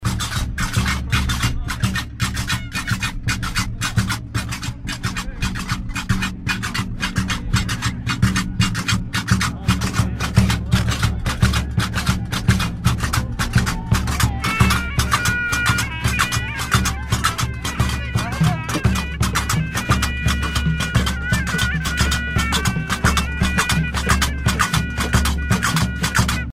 castagnettes métalliques
Pièce musicale éditée